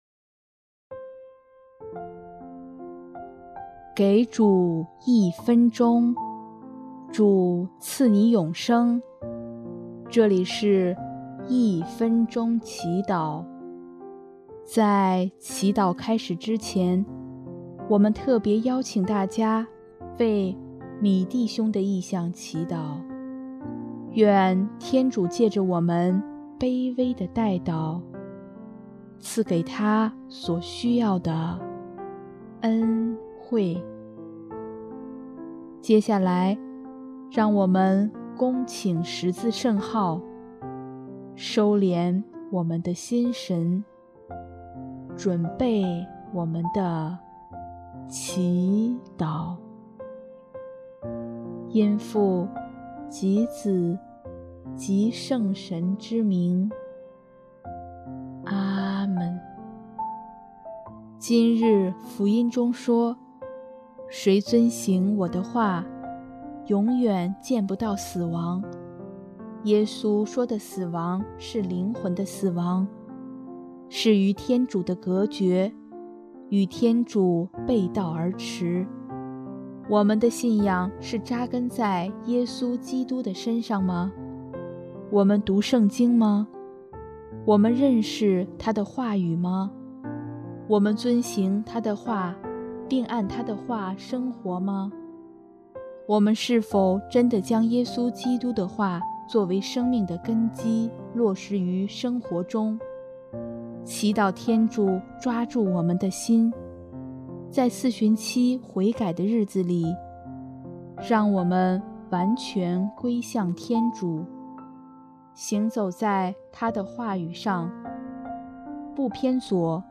音乐：第四届华语圣歌大赛参赛歌曲《当你穿上祭披的时候》